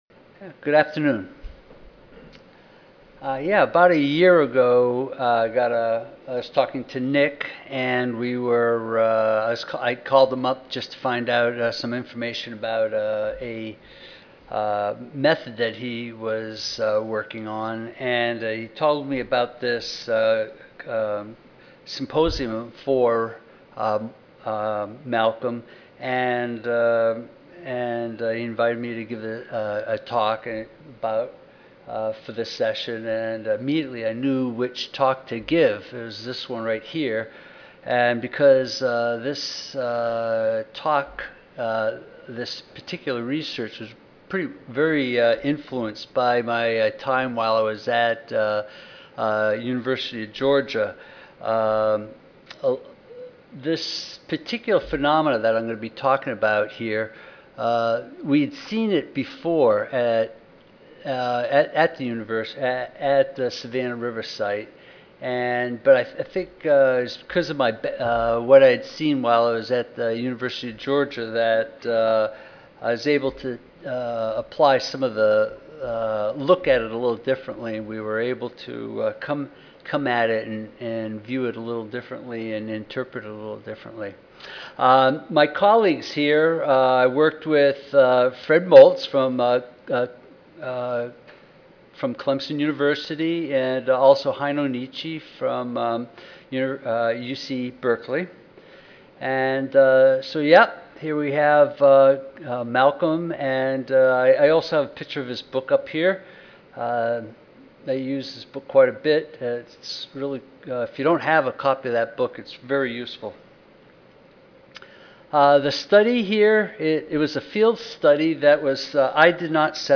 Recorded presentation Four 11-year field lysimeters were conducted with well characterized forms of Pu(III), Pu(IV) or Pu(VI).